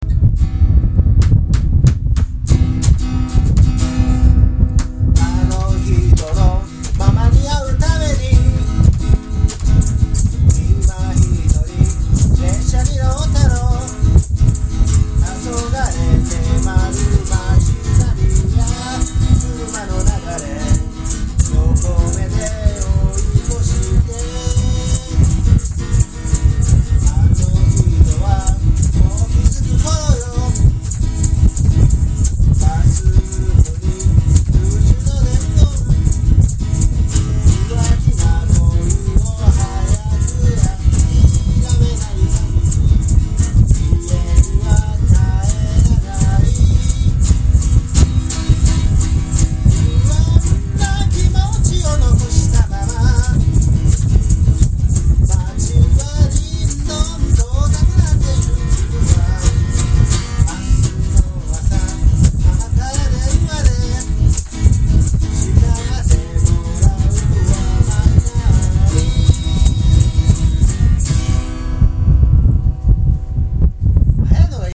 ストローク一つでエゾ松の単板から重厚な音が響き渡った。
声は低く、そして力強い。泥の中から這い出た魂が叫ぶような、そんな歌だった。